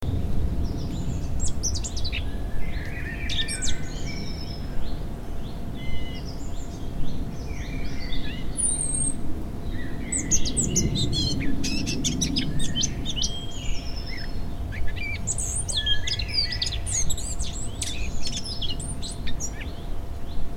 day_countryside_calm.ogg